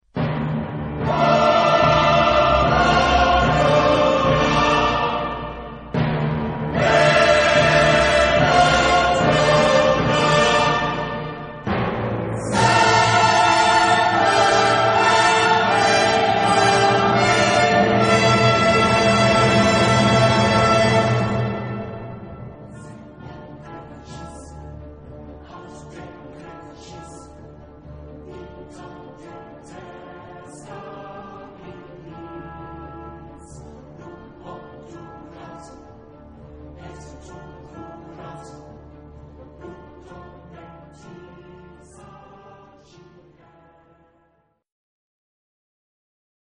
SATB (4 voces Coro mixto) ; Partitura general.
Obra monumental.
Soprano (1) / Ténors (2) / Basses (2)
Orquesta sinfónica